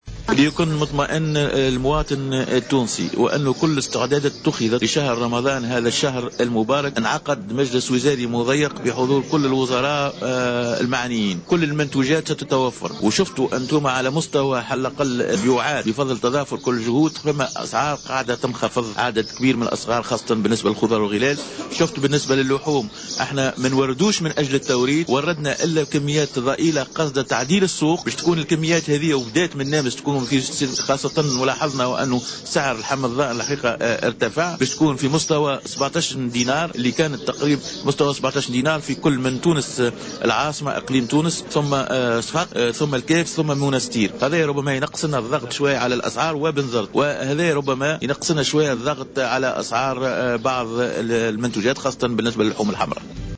أكد وزير التجارة رضا الأحول اليوم الجمعة 08 ماي 2015 خلال افتتاحه الملتقى الدوري لأعوان المراقبة الاقتصادية بسوسة أن كل المنتوجات و المواد الاستهلاكية ستكون متوفرة خلال شهر رمضان القادم.